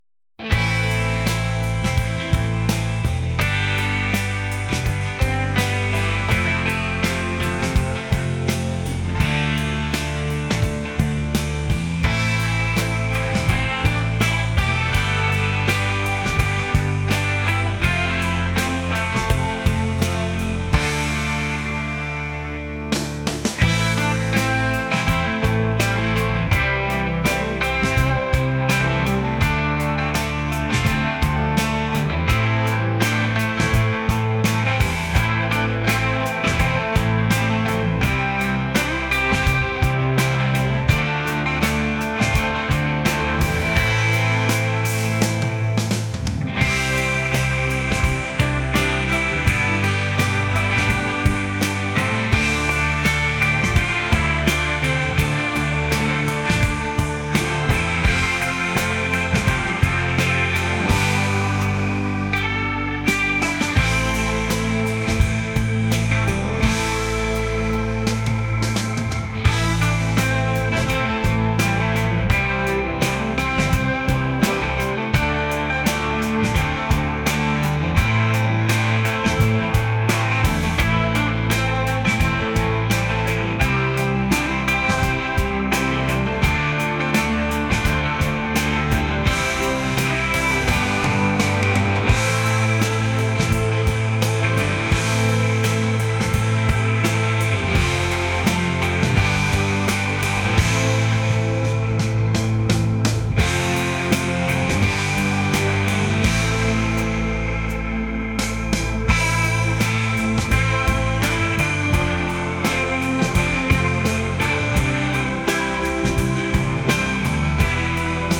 indie | rock | alternative